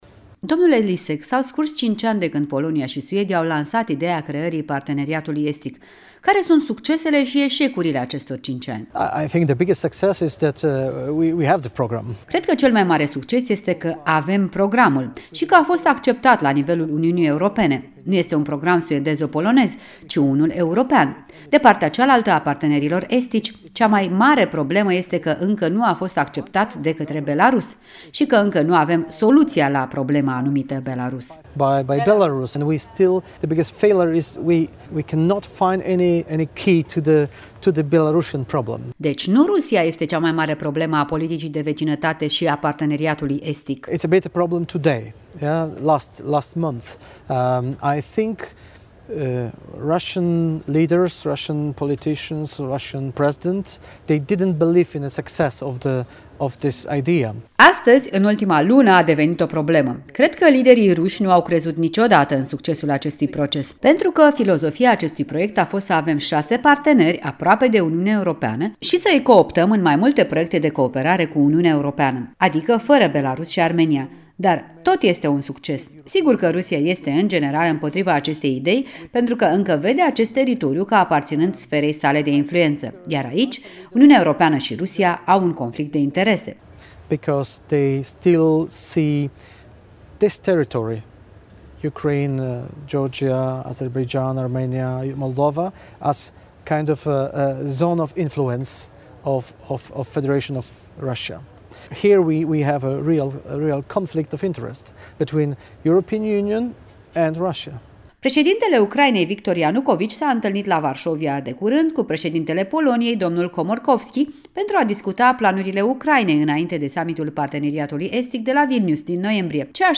În direct de la Strasbourg: un interviu cu europarlamentarul Krzysztof Lisek